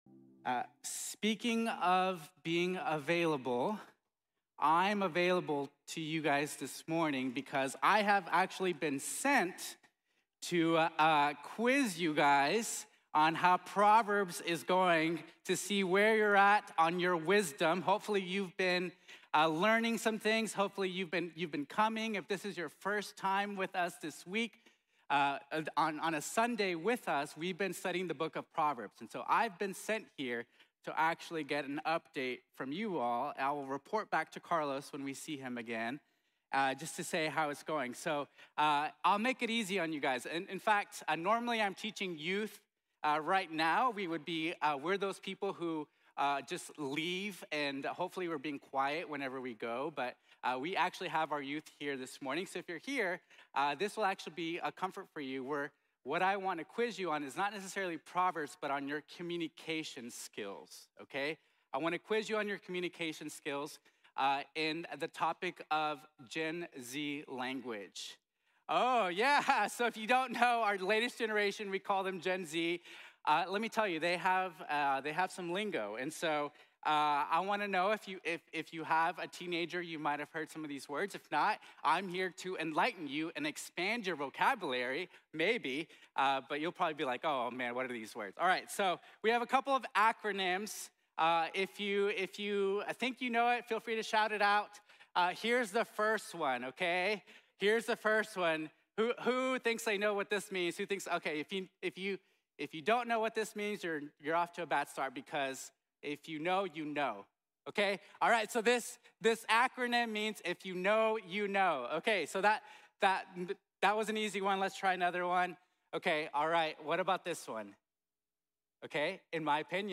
Our Words Matter | Sermon | Grace Bible Church